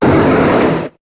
Cri de Métang dans Pokémon HOME.